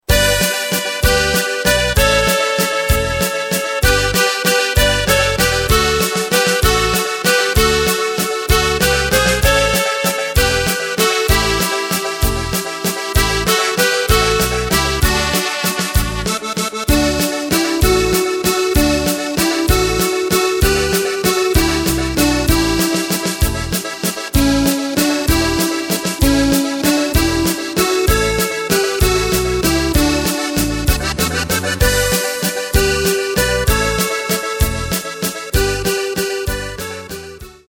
Takt:          3/4
Tempo:         193.00
Tonart:            D
Walzer (Volklied) aus dem Jahr 2008!